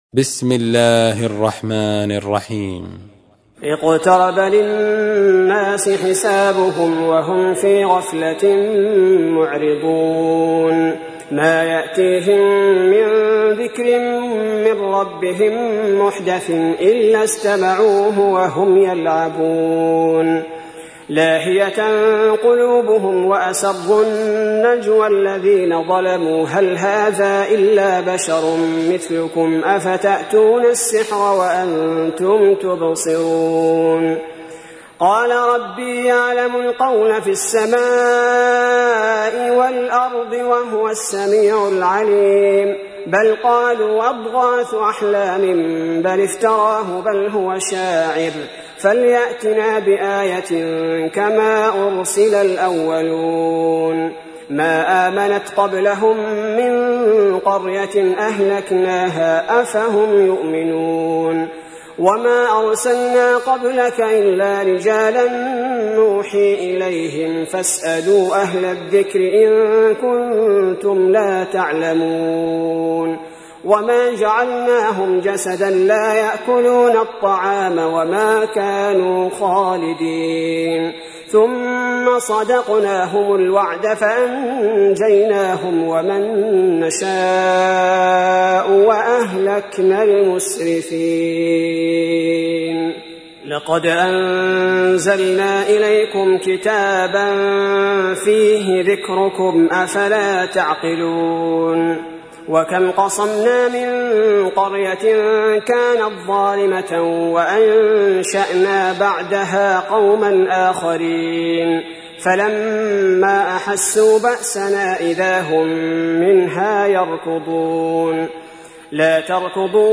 تحميل : 21. سورة الأنبياء / القارئ عبد البارئ الثبيتي / القرآن الكريم / موقع يا حسين